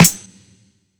ATYS_SNR.wav